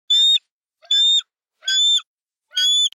دانلود صدای پرنده 27 از ساعد نیوز با لینک مستقیم و کیفیت بالا
جلوه های صوتی
برچسب: دانلود آهنگ های افکت صوتی انسان و موجودات زنده دانلود آلبوم صدای پرندگان از افکت صوتی انسان و موجودات زنده